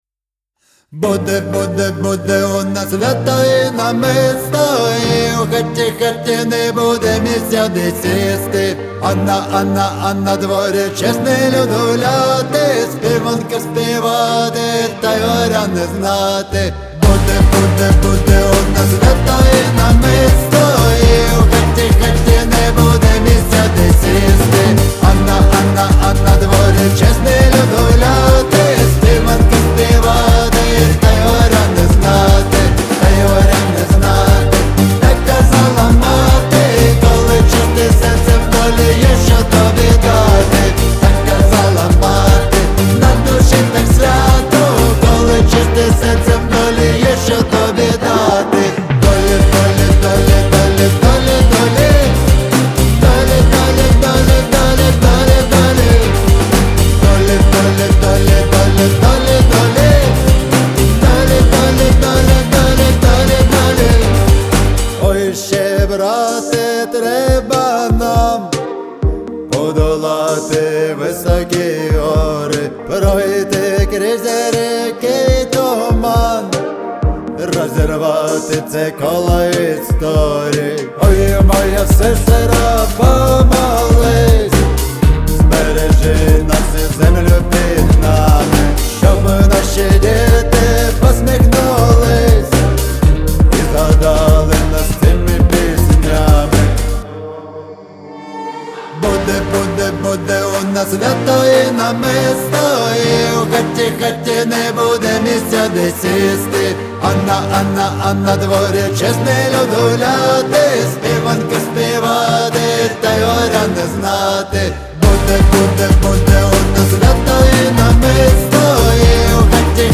• Жанр: Pop, Folk